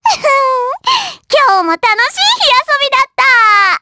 Pansirone-Vox_Victory_jp.wav